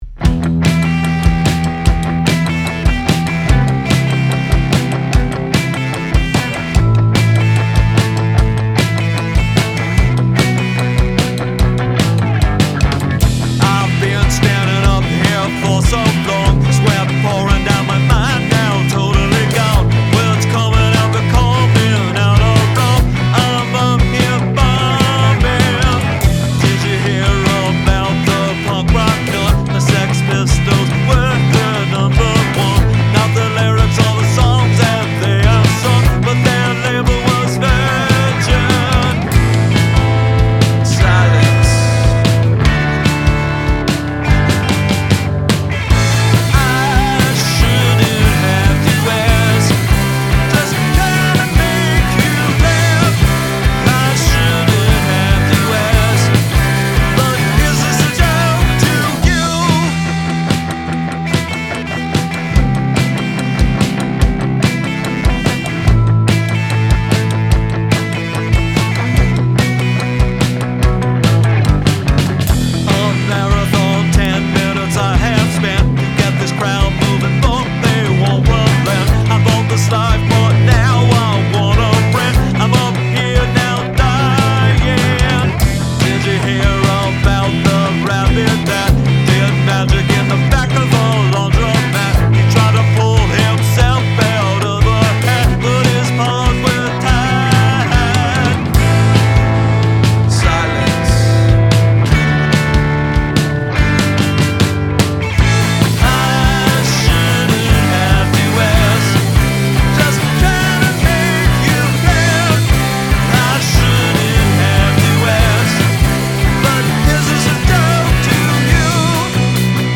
I like the guitar noodles.